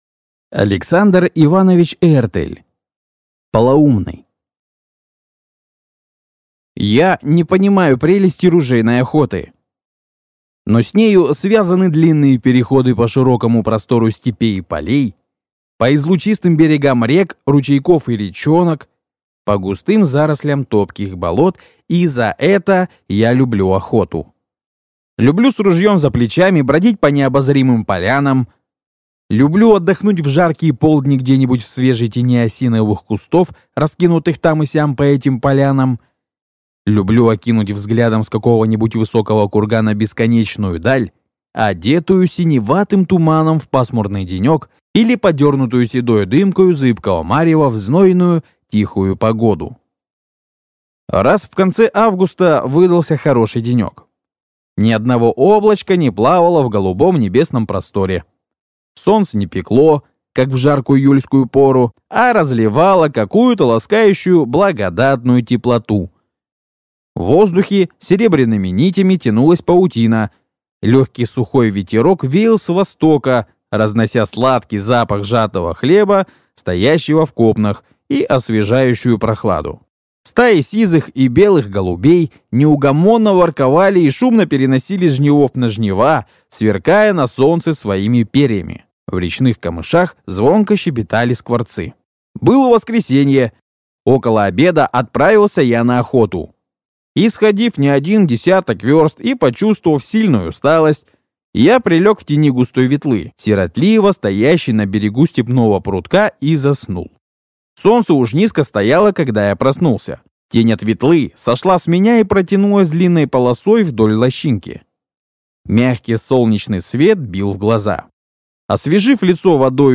Aудиокнига Полоумный